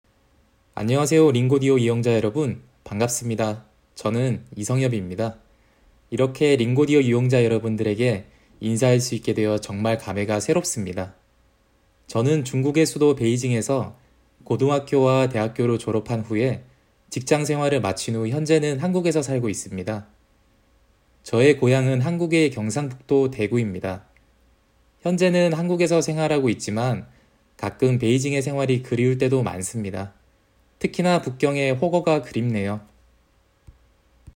(He also recorded his responses at a normal speaking speed and tone.)